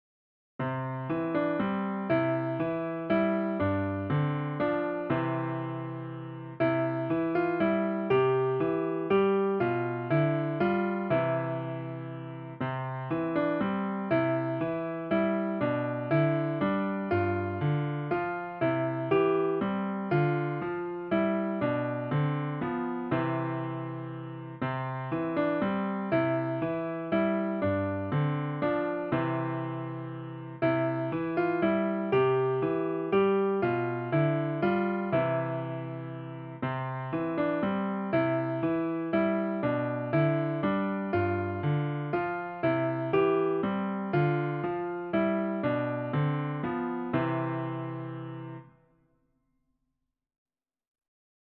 Lullabies & Bed-Time
for piano